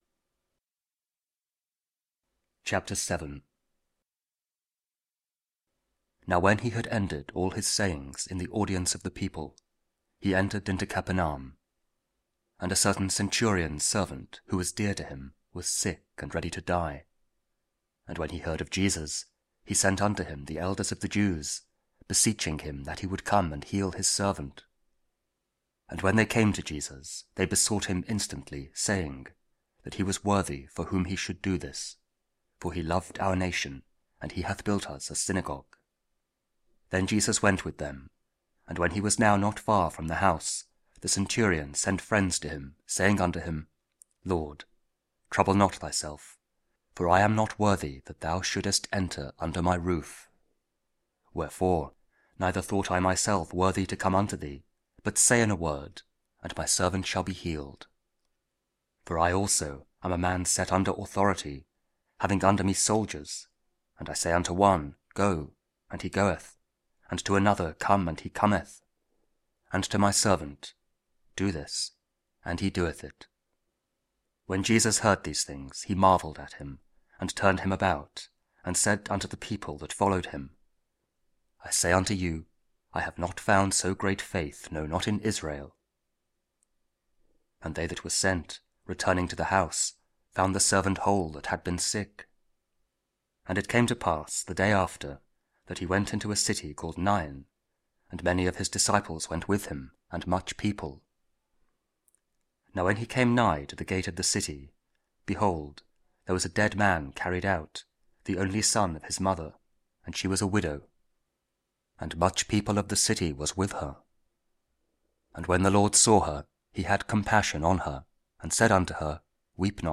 Luke 7: 31-35 – Week 24 Ordinary Time, Wednesday (King James Audio Bible KJV, Spoken Word)